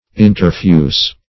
Interfuse \In`ter*fuse"\, v. t. [L. interfusus, p. p. of